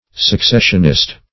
Search Result for " successionist" : The Collaborative International Dictionary of English v.0.48: Successionist \Suc*ces"sion*ist\, n. A person who insists on the importance of a regular succession of events, offices, etc.; especially (Eccl.), one who insists that apostolic succession alone is valid.